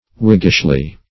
whiggishly - definition of whiggishly - synonyms, pronunciation, spelling from Free Dictionary Search Result for " whiggishly" : The Collaborative International Dictionary of English v.0.48: Whiggishly \Whig"gish*ly\, adv. In a Whiggish manner.